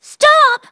synthetic-wakewords
ovos-tts-plugin-deepponies_Rarity_en.wav